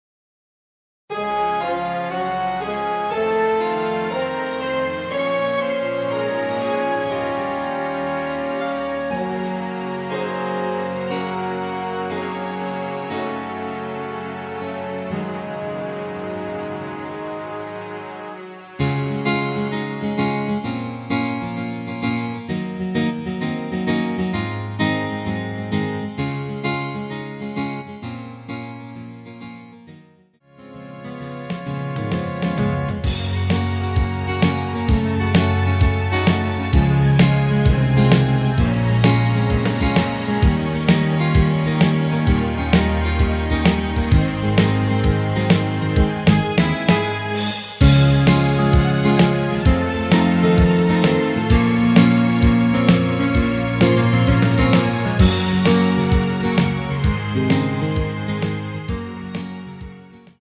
부담없이즐기는 심플한 MR
C#
앞부분30초, 뒷부분30초씩 편집해서 올려 드리고 있습니다.